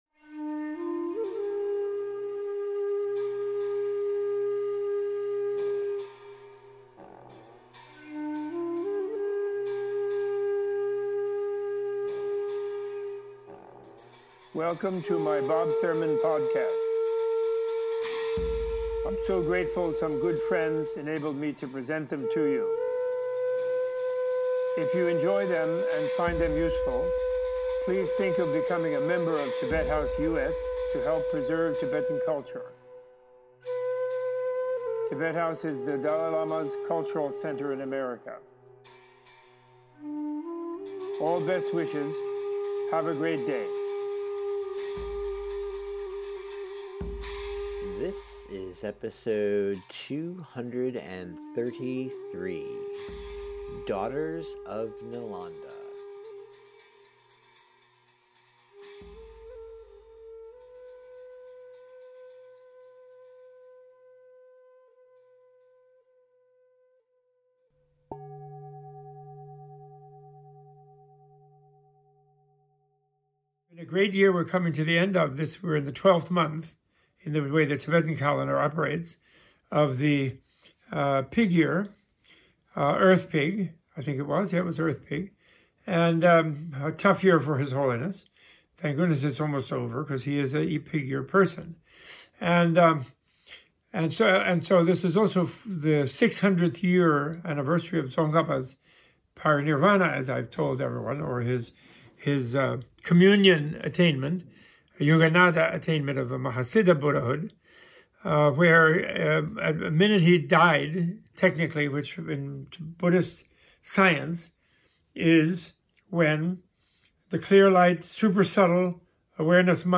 In honor of Tibetan New Year and the 600th anniversary of the enlightenment of Tsongkhapa, Professor Thurman gives a teaching on the history of women in Buddhism and details the on-going work to re-evaluate and re-establish Vajrayana Tantric traditions in hearts, minds and lives of institutional monastics and modern householder practitioners.